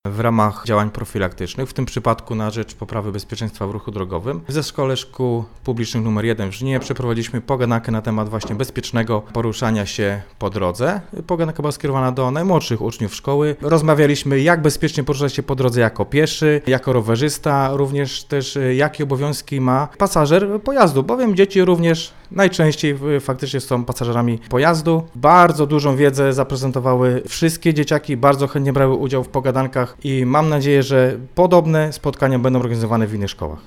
Pogadanka policjantów z uczniami na temat bezpieczeństwa